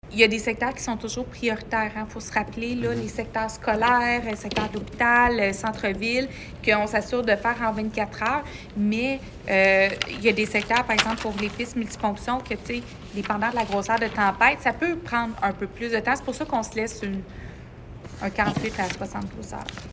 Julie Bourdon, mairesse de Granby.